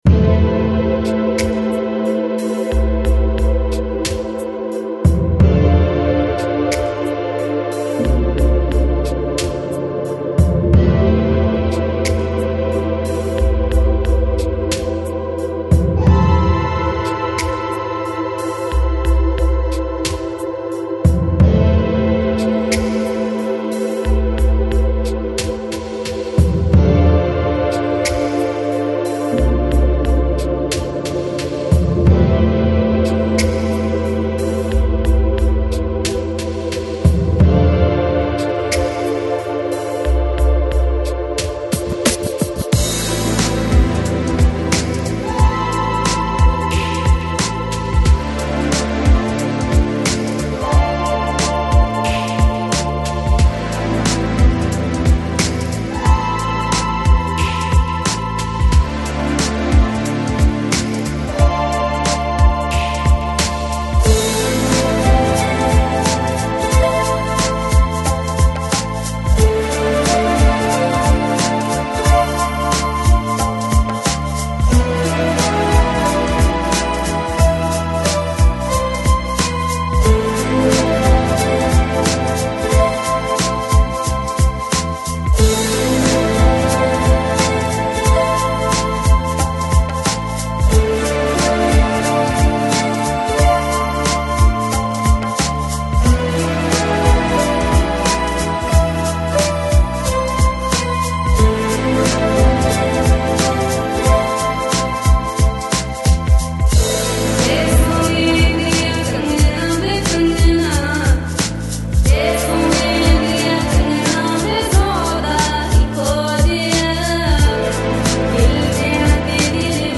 FLAC Жанр: Chill Out, Lounge, Downtempo, World Носитель